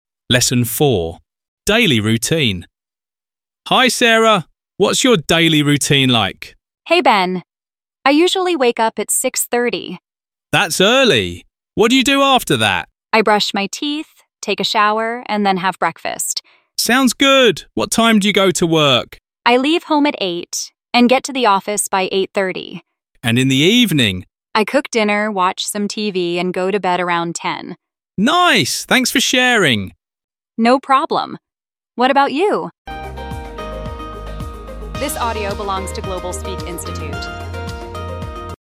Giọng tự nhiên